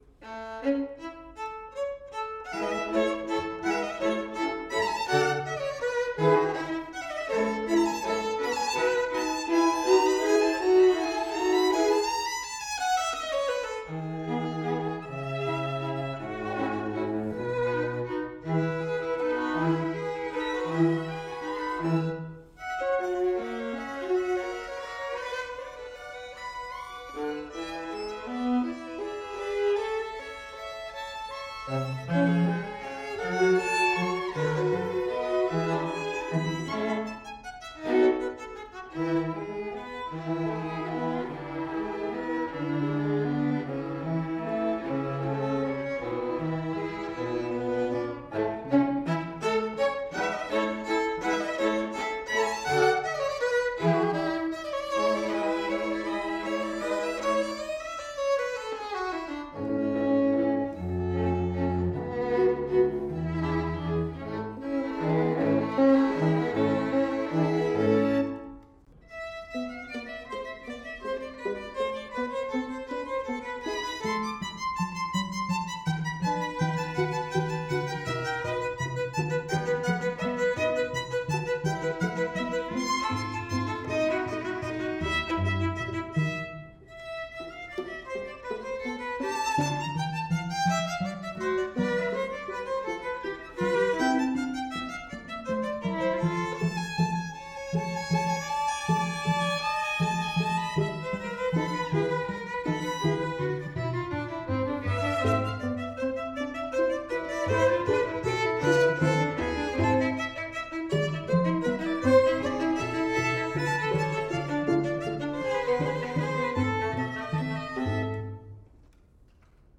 Soundbite 3rd Movt